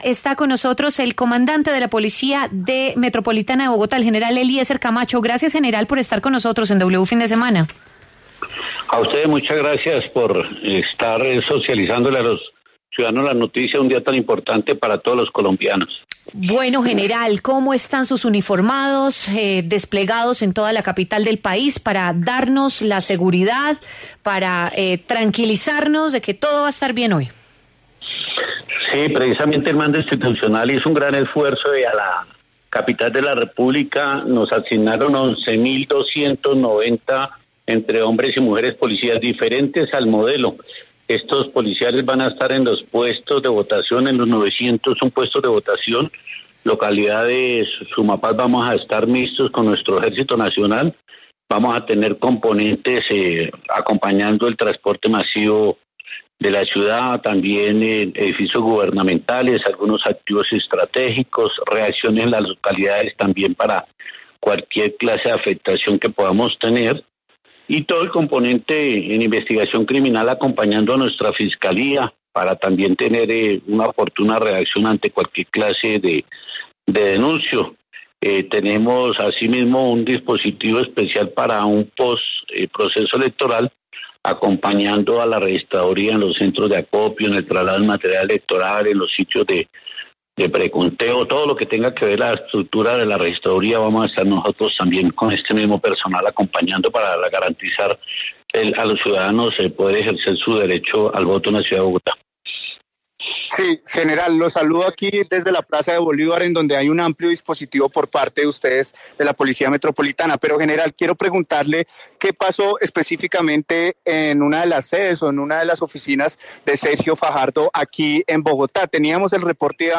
El comandante de la Policía Metropolitana de Bogotá, el general Eliecer Camacho, habló en W Fin de Semana sobre las garantías que darán las autoridades en la jornada electoral.